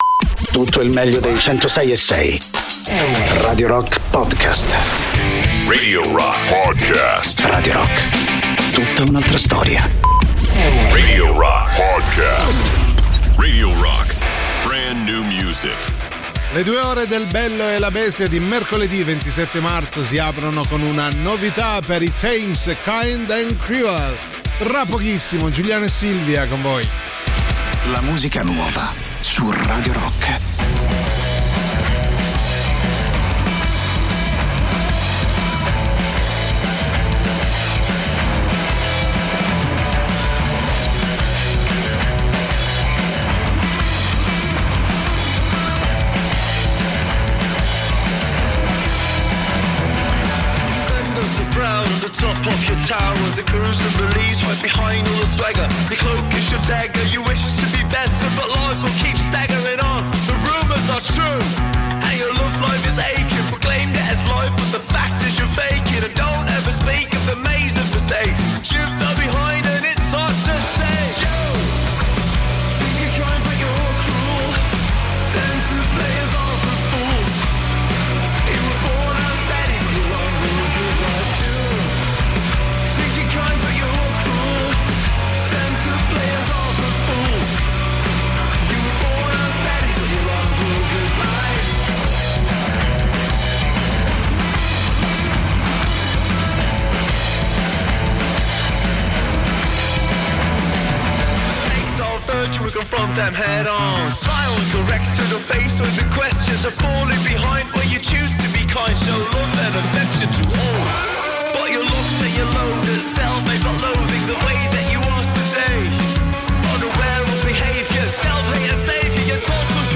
in diretta sui 106.6 di Radio Rock dal Lunedì al Venerdì dalle 13.00 alle 15.00.